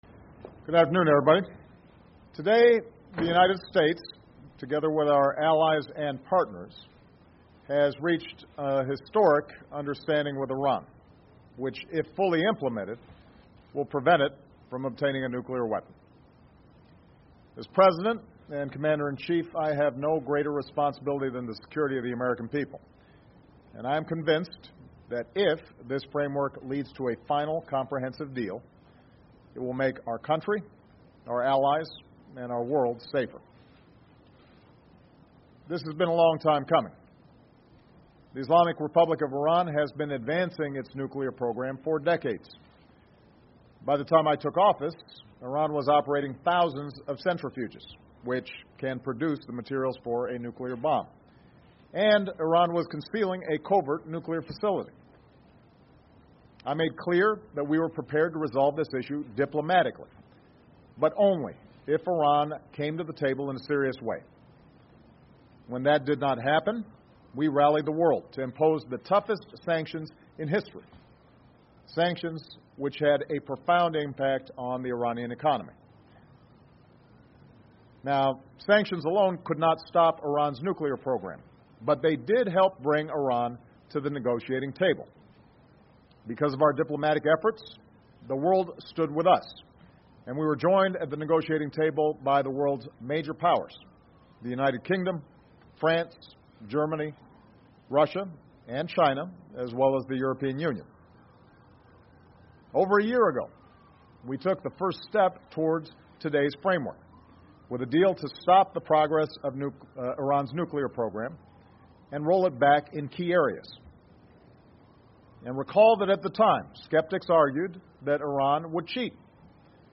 የዩናይትድ ስቴትስ ፕሬዚዳንት ባራክ ኦባማ በስድስቱ ኃያላን መንግሥታትና በኢራን መካከል በማዕቀፉ ነጥቦች ላይ ስምምነት መደረሱ እንደተነገረ ከዋይት ሃውስ ቤተመንግሥት ያደረጉትን ንግግር(በእንግሊዝኛ)